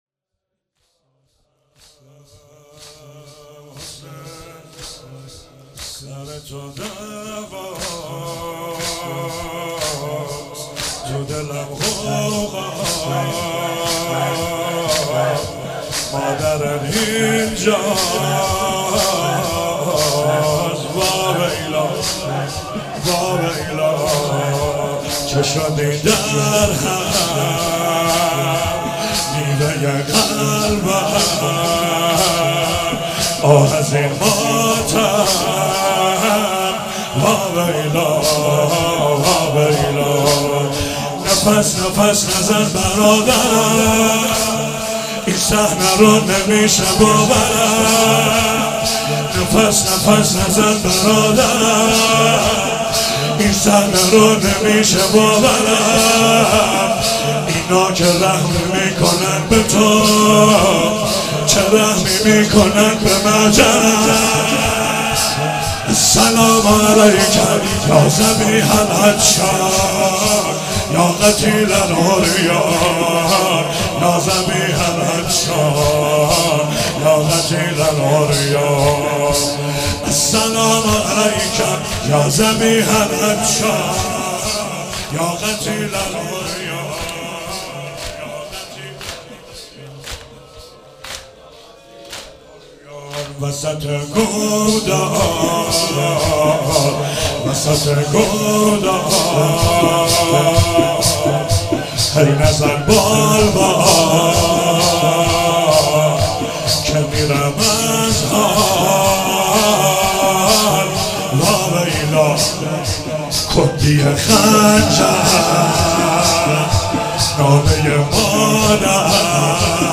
مراسم هفتگی 24 فروردین 96
چهاراه شهید شیرودی حسینیه حضرت زینب (سلام الله علیها)